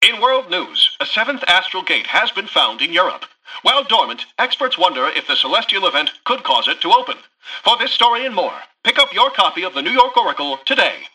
[[Category:Newscaster voicelines]]
Newscaster_headline_40.mp3